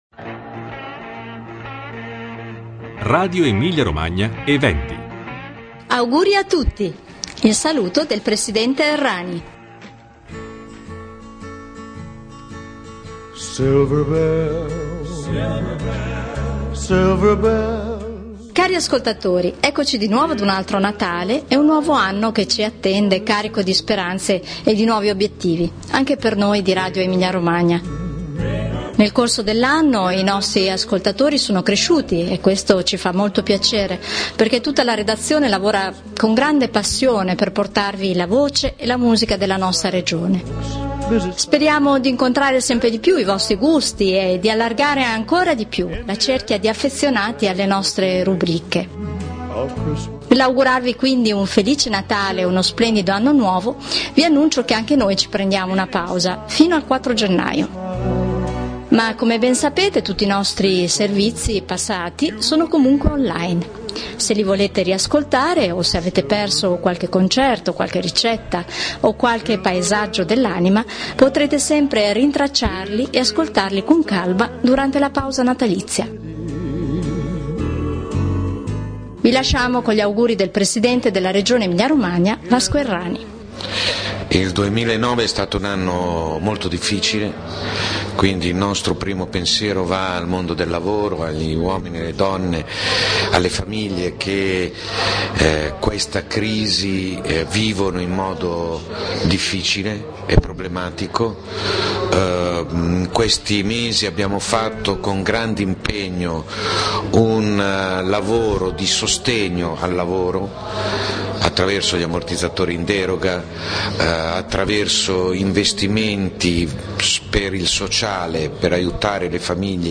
Il saluto del Presidente Errani